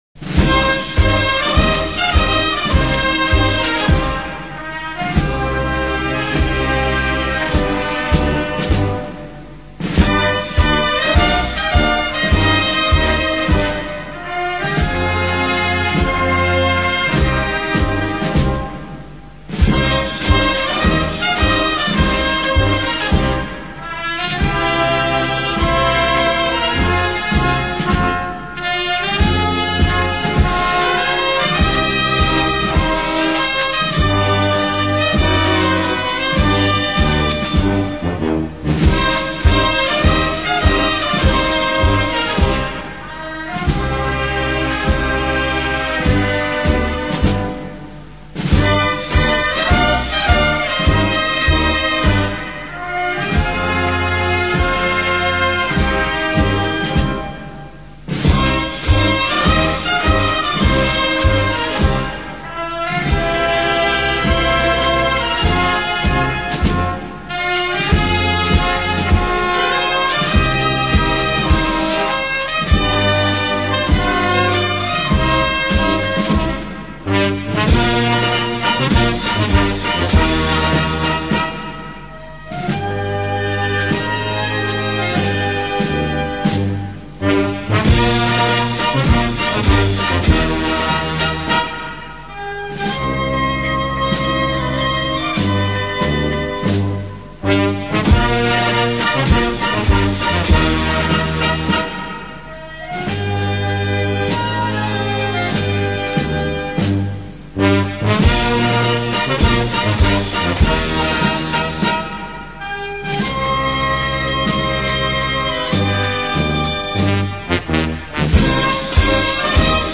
¡Ahora!, grita Herrera y sube la marcha.
"Todos los años cuando regresa la Vírgen de la Esperanza a la calle Pureza suena esta portentosa marcha que recoge la salve marinera de Triana", ha explicado Herrera mientras sonaba de fondo y se animaba a cantar.